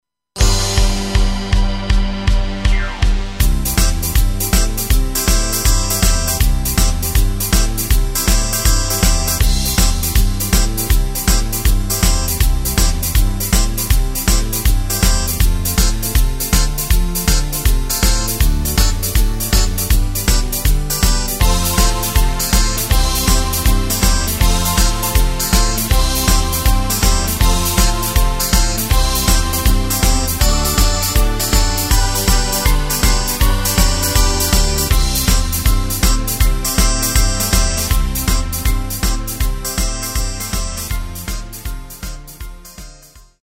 Tempo:         160.00
Tonart:            C
Rock`n Roll aus dem Jahr 1979!
Playback mp3 Demo